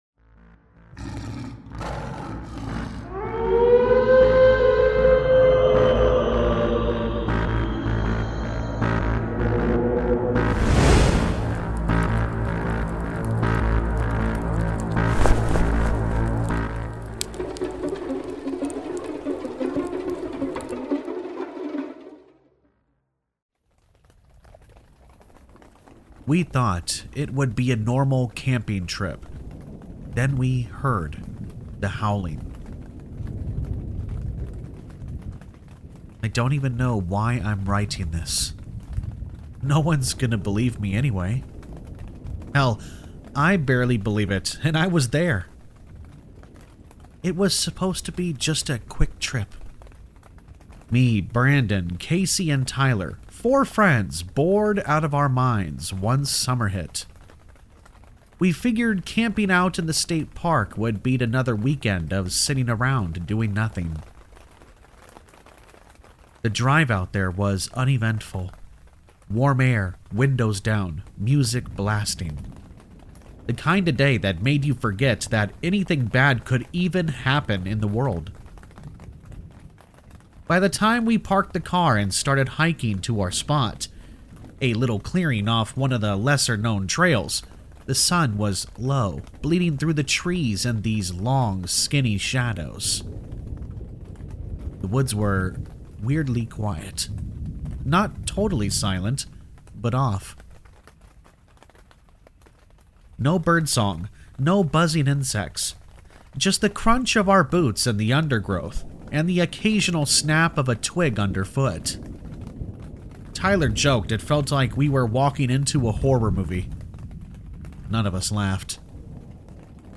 Campfire Tales is a channel that is human voiced that does NOT use a fake Ai voiced simulator program. This channel is focused on Allegedly True Scary Stories and Creepypastas.